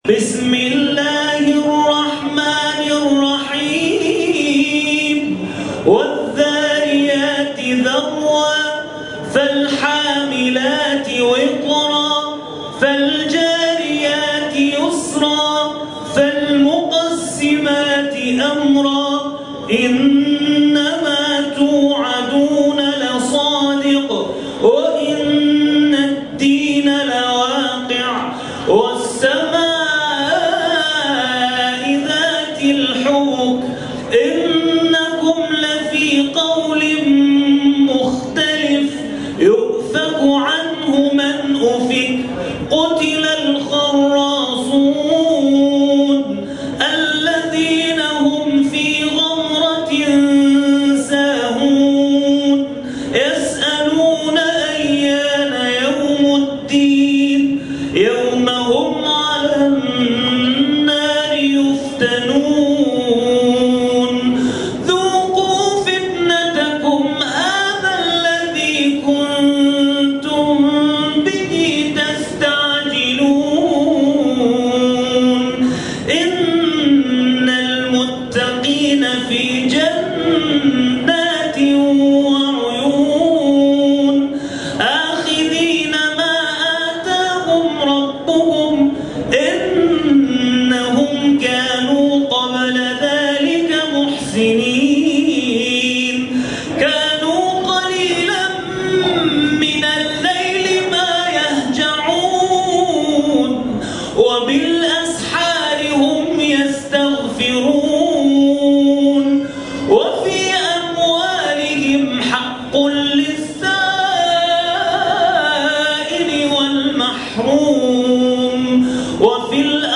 نظم جلسات و حضور مردم پای رحل قرآن و همنوا شدن با قاریان، انسجام و وحدت در پاسخ به مجری و خواندن اشعار عربی میان تلاوت‌ها، سن باشکوه و نورپردازی زیبا، پذیرایی با چای و قهوه سنتی و... همه و همه که از ویژگی‌های مراسم جزءخوانی عراقی‌های مقیم تهران است، دست به دست هم دادند تا در مراسم جزءخوانی آنها، روح خاصی برقرار باشد و انسان دقایقی از دغدغه‌ها و مسائل روزمره دور افتد.